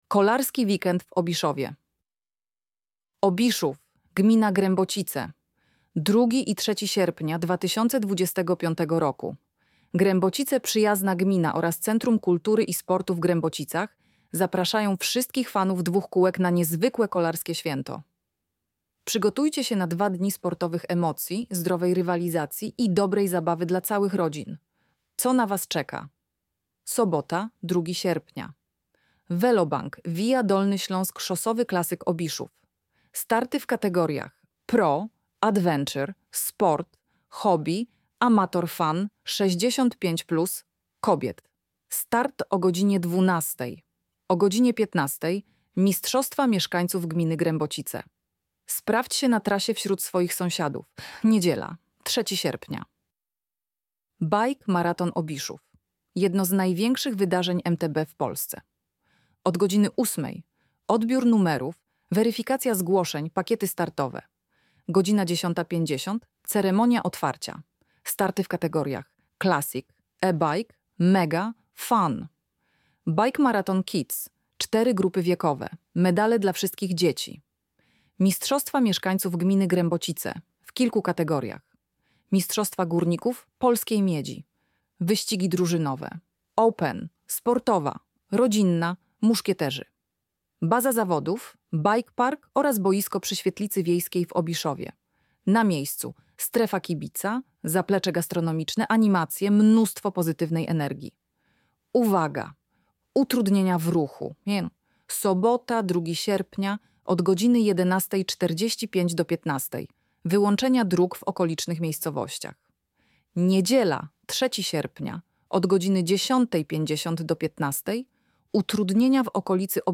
MTB-Lektor.mp3